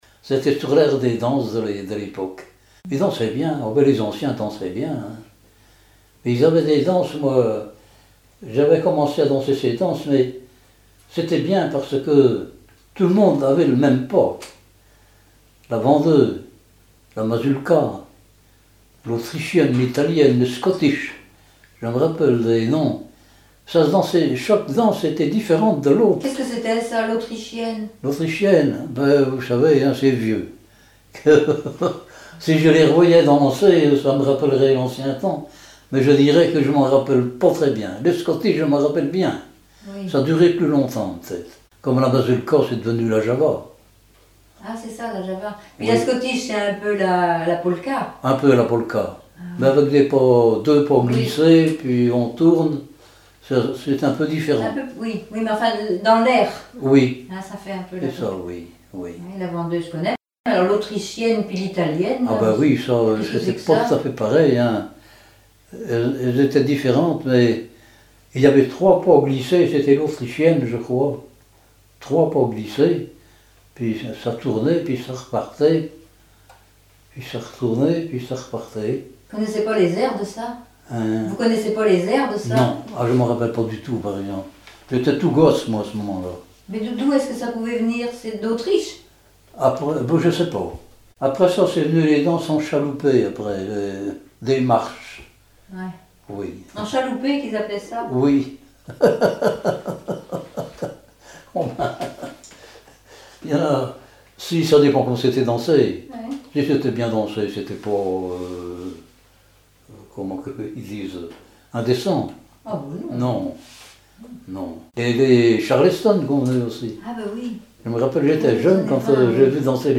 Catégorie Témoignage